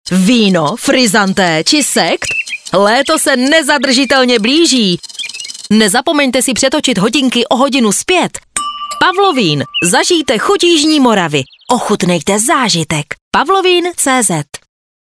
Změna času se blíží a my jsme tohle přetáčení ručiček využili k naší reklamní kampaní na vlnách Radia Impuls.